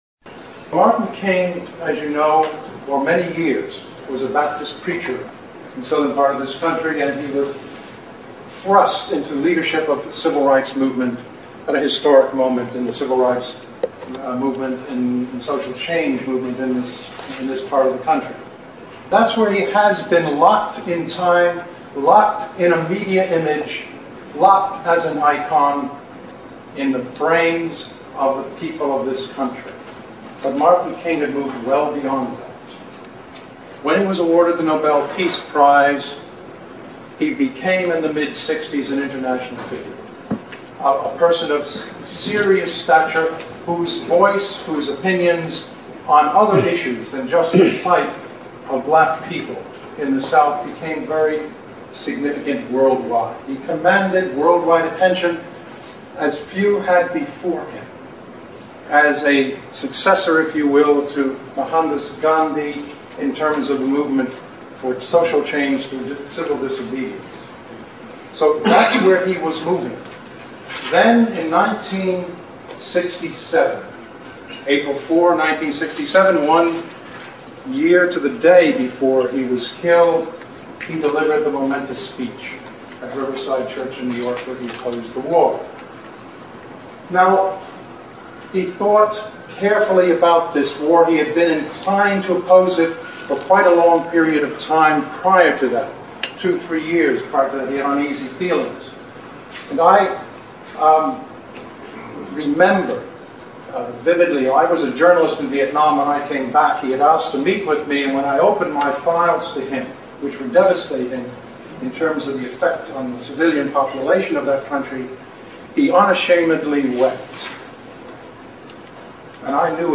MP3 audio of the 1999 trial - William Pepper's Closing Statement to the Jury
pepper_closing_statement.mp3